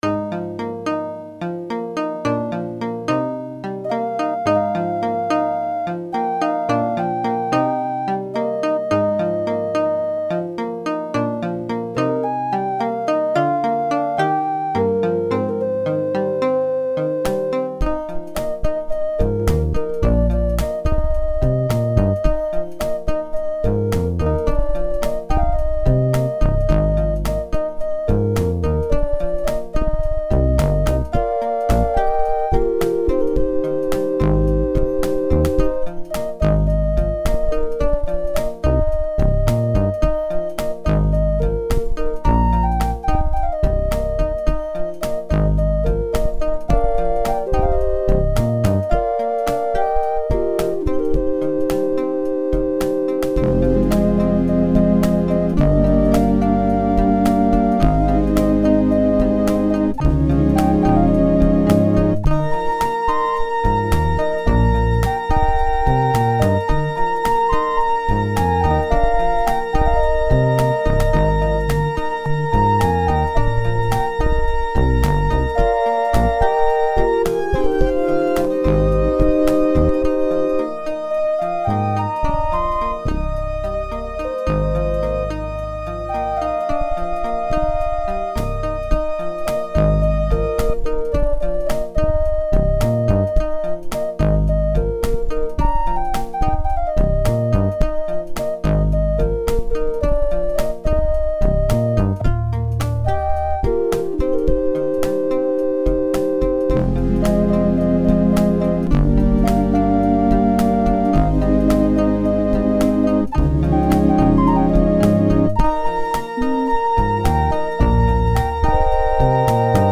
ESS FM MIDI Engine (from an ESS Onboard Soundcard)